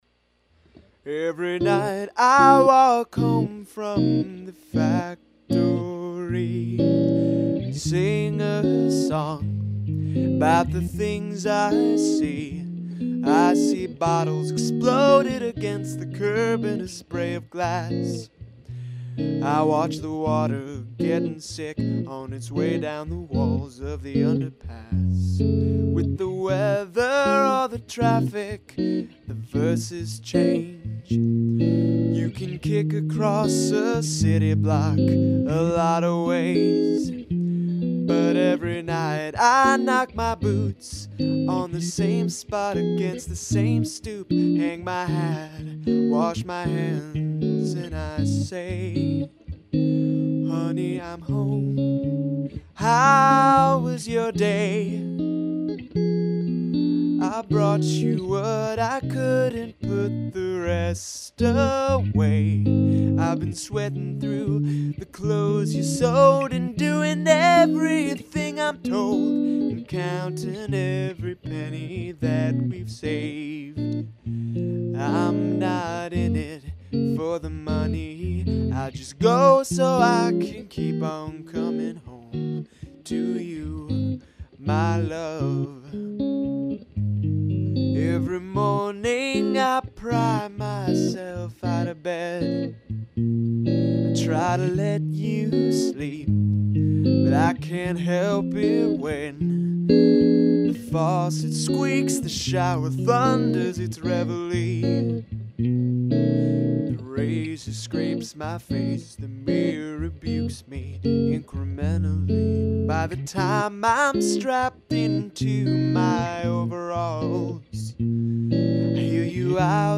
This song is in Db major, and it's got the most chords of anything I've ever written.
A A' B A A' C B' B
Of the jazzy/early-American-popular-music-inspired numbers I've worked on, this is the epic.
This recording is far from complete (I want to add bass and drums, and some sort of instrumental outro (ideally trumpet, probably cheap keyboard trumpet for this demo).
I tried to sing this with kind of a "stage" voice (as much as I am able), because this is one of the more overtly theatrical songs I've done. This song is also kind of tangentially about what it means to be a man, so I've tried to sing in a more manly way than I might on say, a glam song.